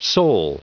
Prononciation du mot soul en anglais (fichier audio)
Prononciation du mot : soul